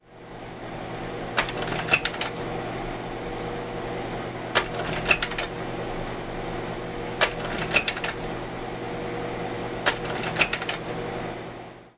KodakCarousel.mp3